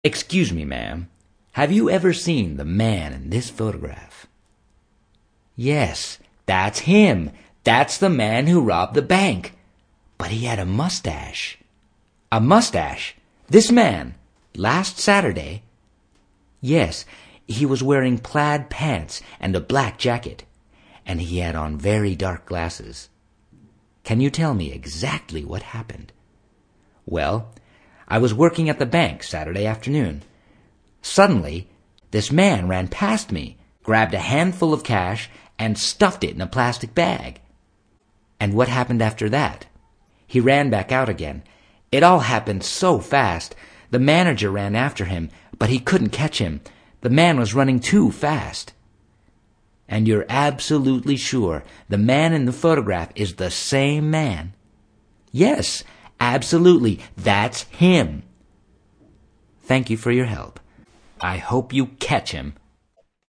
Hi Classes – below are recordings of myself reading the dialogs you have chosen. Listen to the examples and practice with the stress I use.